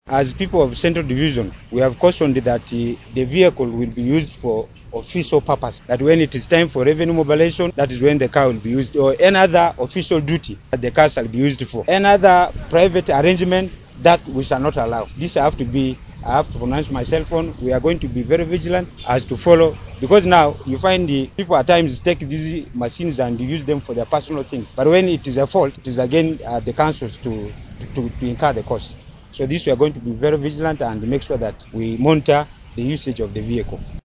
The council speaker, Rashul Osuga, also made his stance clear during the ceremony. He issued a stern warning to all civil servants within the division against any misuse of the newly acquired vehicle.
CUE;OSUGGA ON VEHICLE.mp3